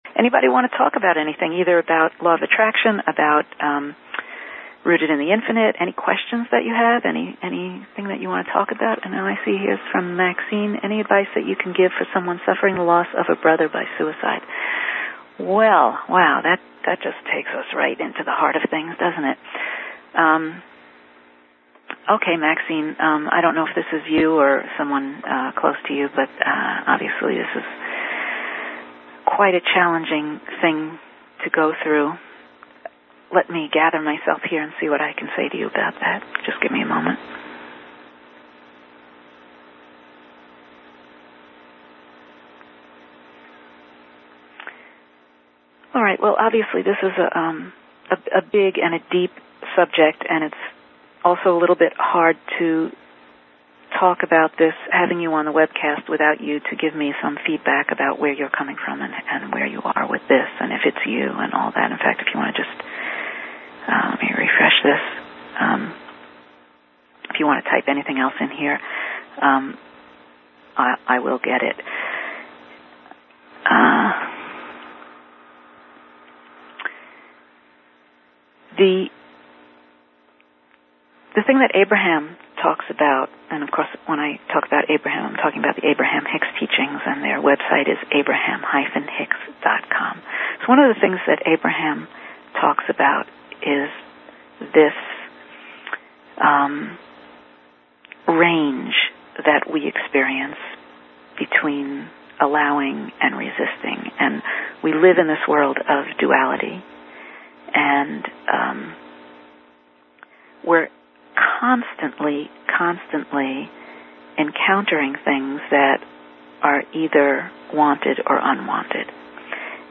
Rooted in the Infinite Teleseminars
2009-07-24 - Inner Alignment: The Key to the Inner Garden click to access streaming audio and mp3 download 2009-07-21 - Informal Q & A click to play or right click to download